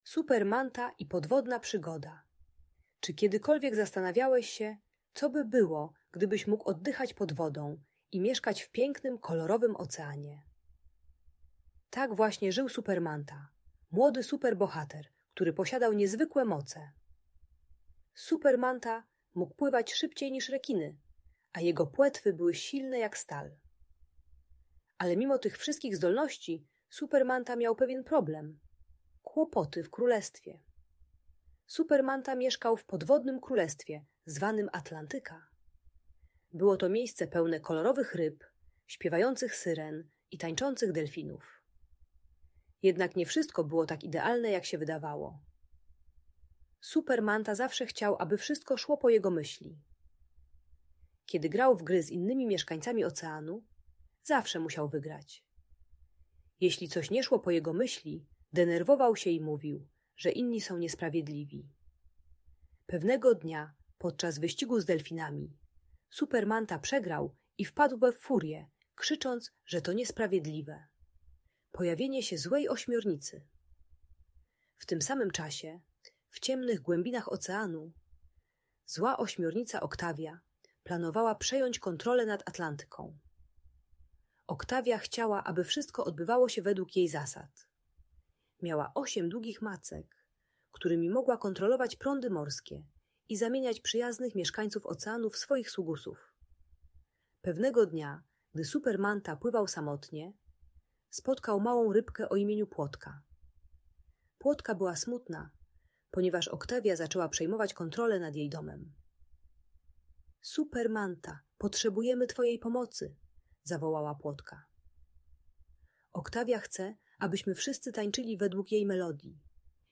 Super Manta i Podwodna Przygoda - Audiobajka